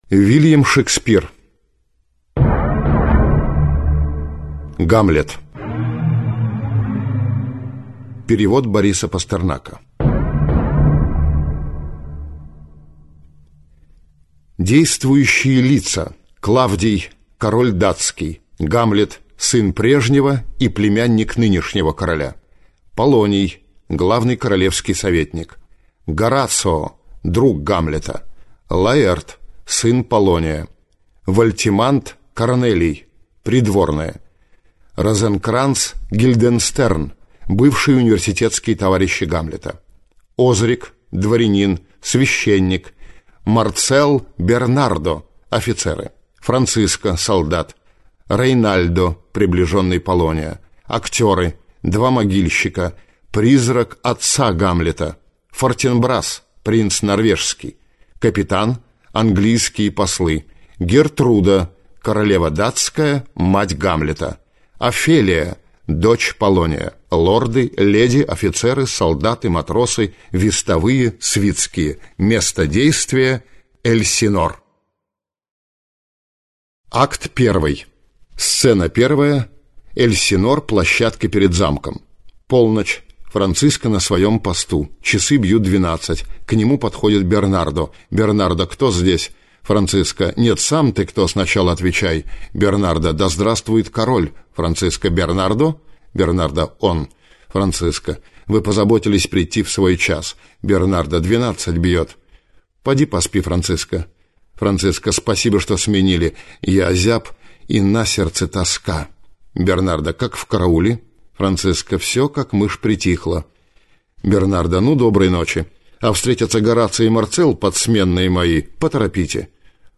Аудиокнига Гамлет - купить, скачать и слушать онлайн | КнигоПоиск